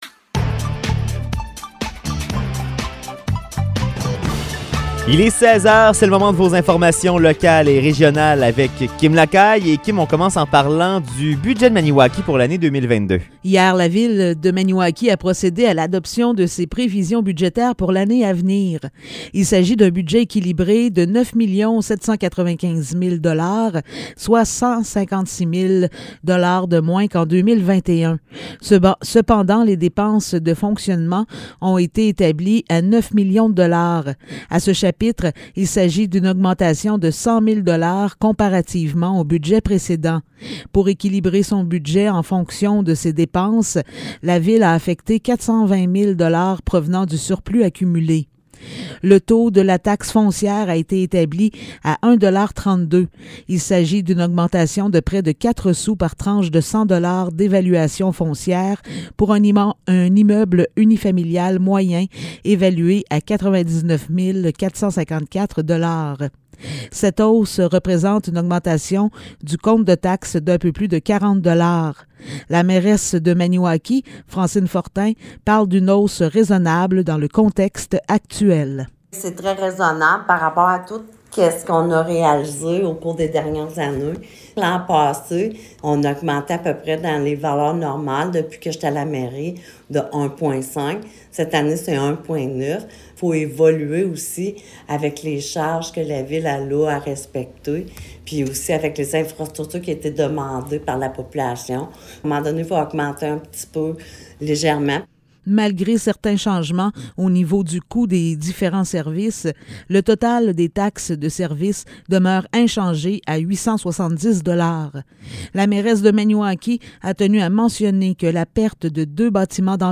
Nouvelles locales - 14 décembre 2021 - 16 h